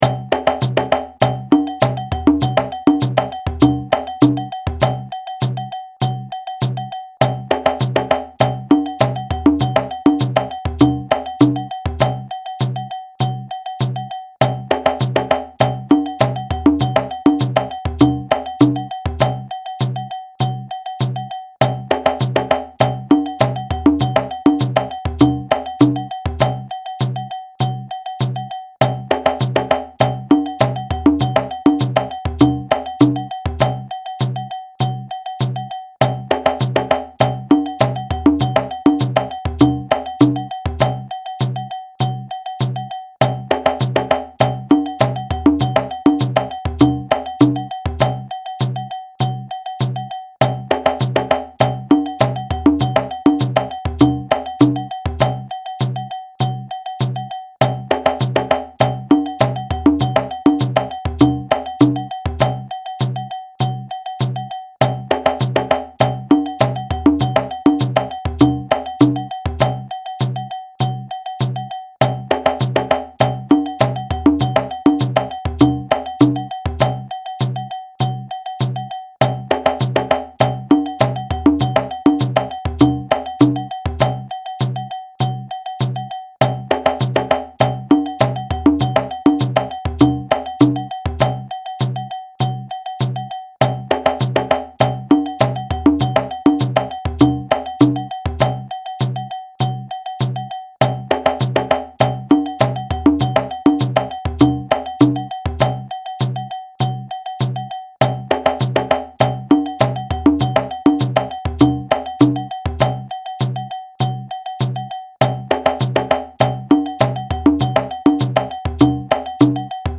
This phrase represents a typical 2-bar phrase found in traditional djembe music.
audio (with shekeré, bass & bell)
This impressive collection of 2-Bar Special Phrases (60) are drawn from djembe solo settings and were chosen for their inherent “call and response” qualities as well as for their ability to teach the drummer how to dance around the pulse.
SP-1-44-fast-hh.mp3